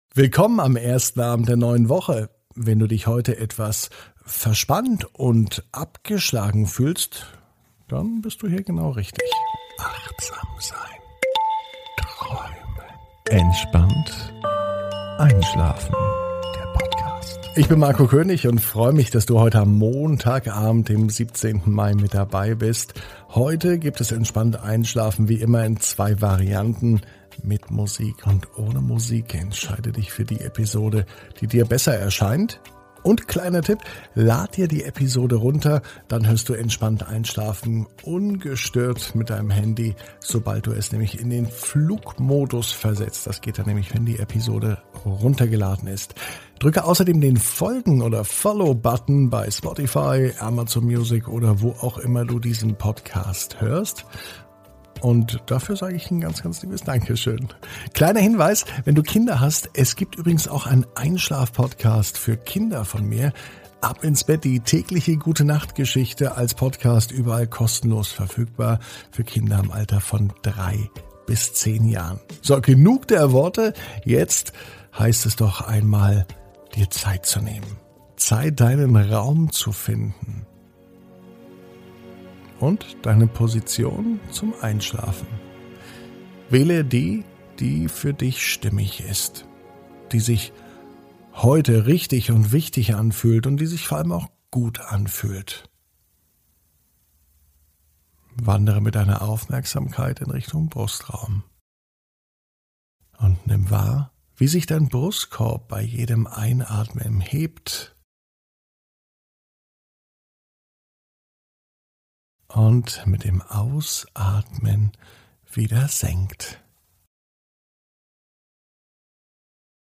(ohne Musik) Entspannt einschlafen am Montag, 17.05.21 ~ Entspannt einschlafen - Meditation & Achtsamkeit für die Nacht Podcast